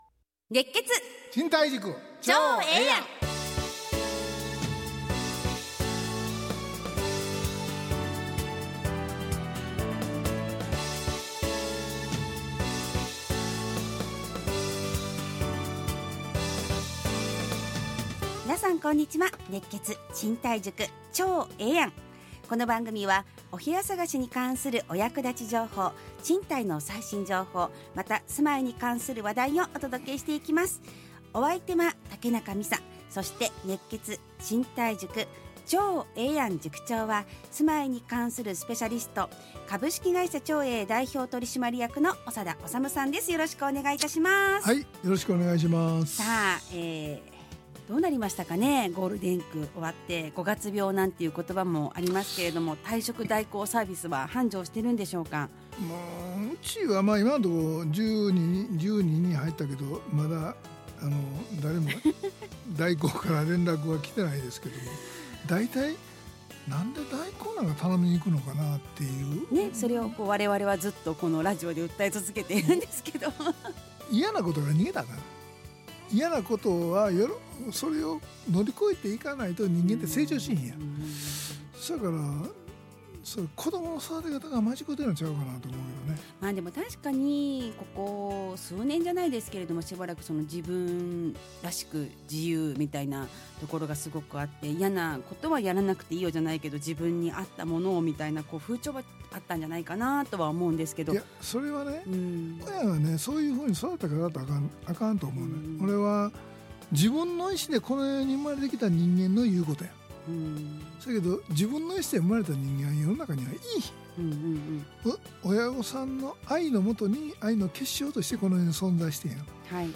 ラジオ放送 2025-05-16 熱血！